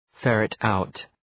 Leave a reply ferret out Dëgjoni shqiptimin https